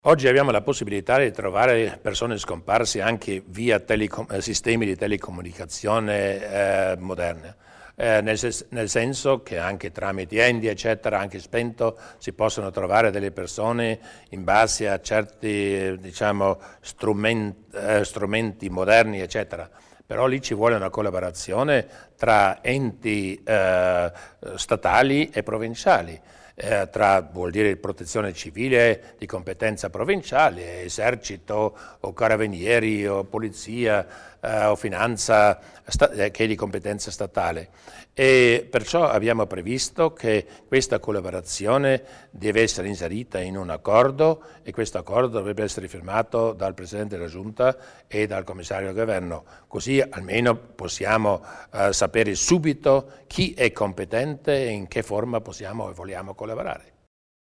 Il Presidente Durnwalder illustra i nuovi progetti per la ricerca di persone scomparse